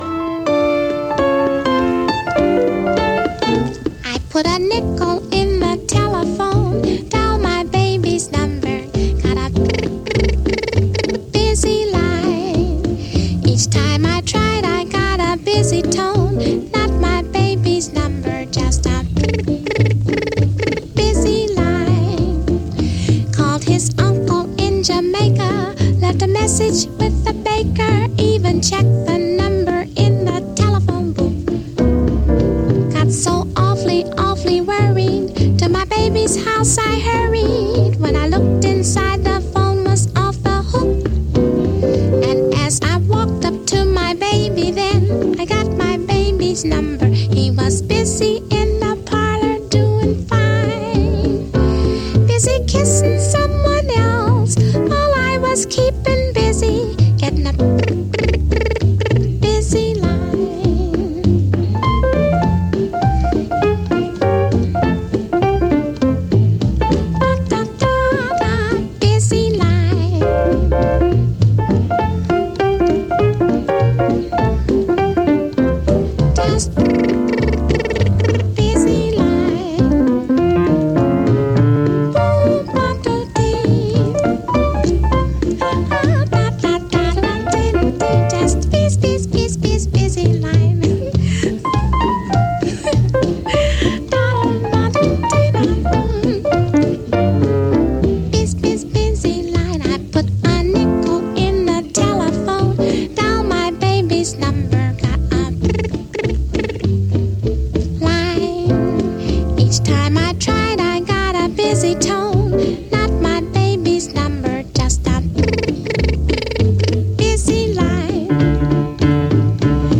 Файл в обменнике2 Myзыкa->Джаз
Певица
Жанр: Вокальный джаз